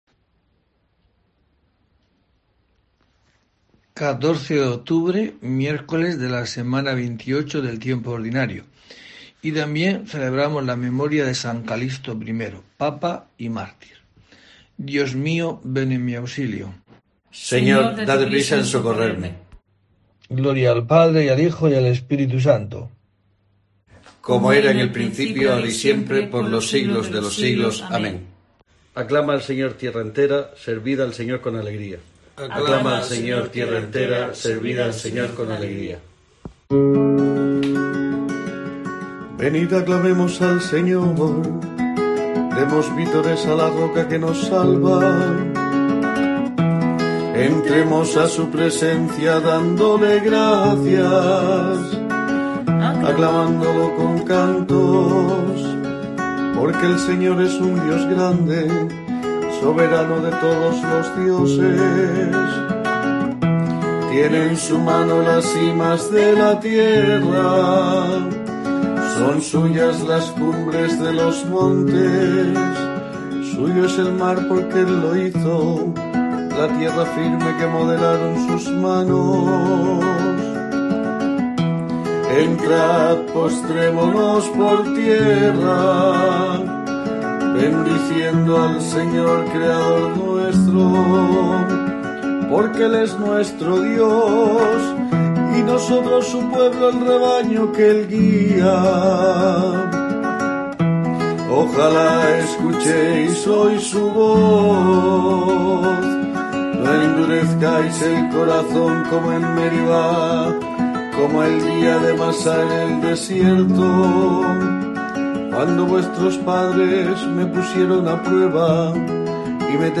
14 de octubre COPE te trae el rezo diario de los Laudes para acompañarte